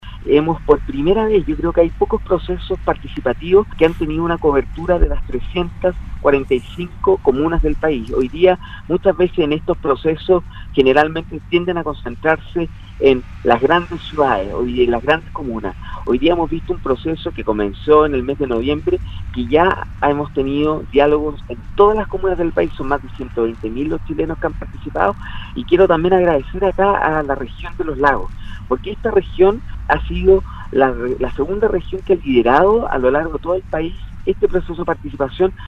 En conversación con Radio Sago, el Subsecretario de Servicios Sociales, Sebastián Villarreal, se refirió al trabajo que está realizando la cartera de Desarrollo Social en el marco del proceso de escucha a través de diálogos ciudadanos.